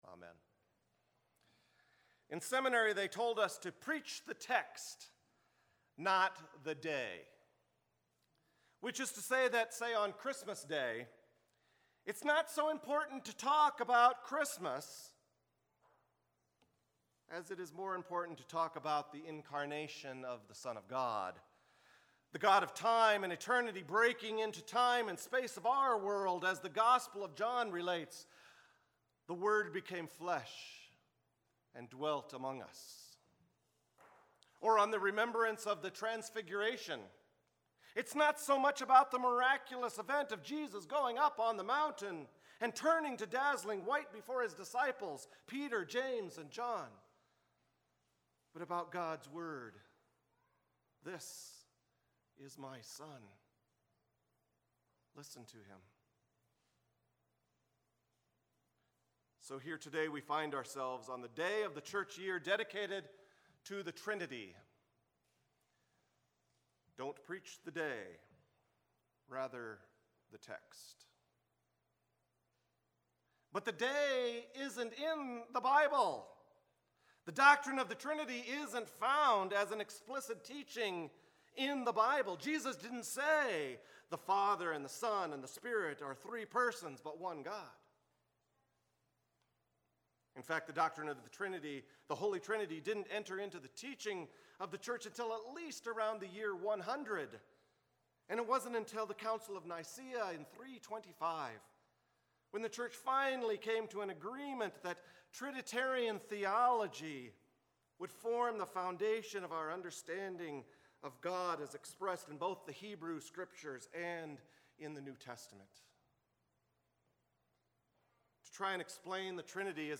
Sermons | Bethlehem Lutheran Church